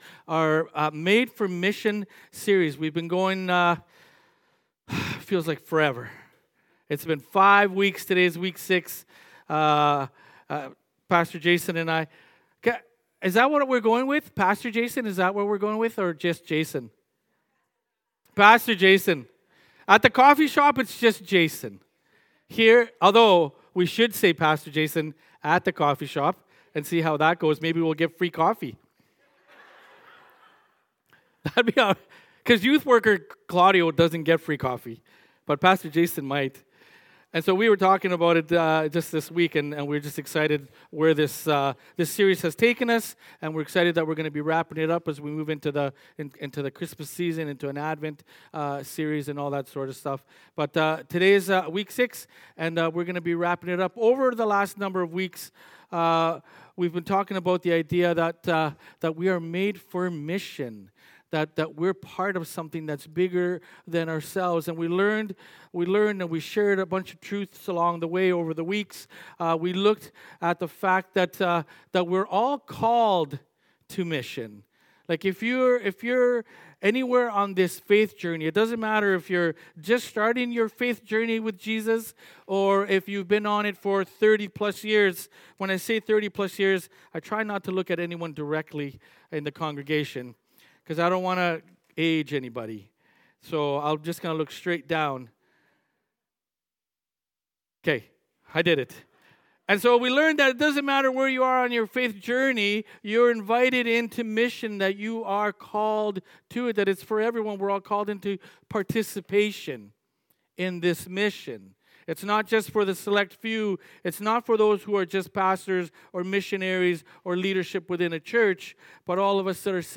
Sermons | City Light Church